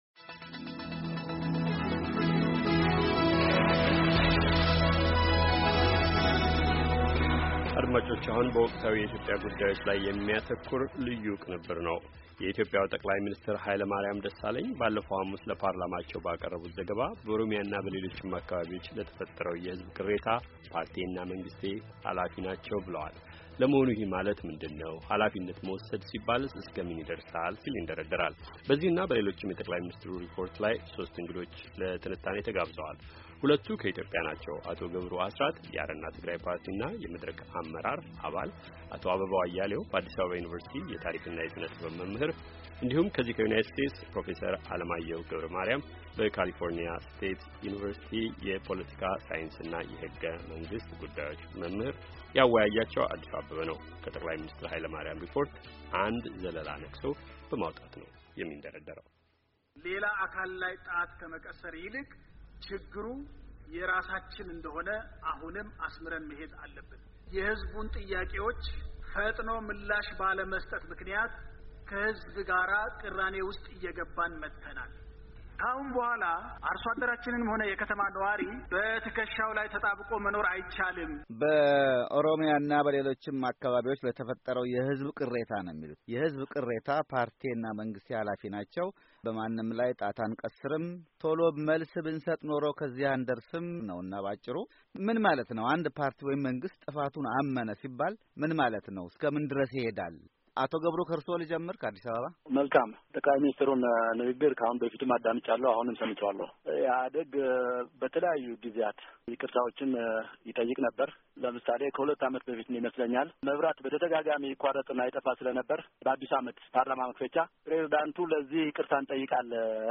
"ኃላፊነት መውሰድ" ሲባል እስከምን ድረስ ይሄዳል? በሚል ጥያቄ መነሻነት፣ በትናንቱ ዕለት የሦስት እንግዶችን ትንታኔ አቅርበናል።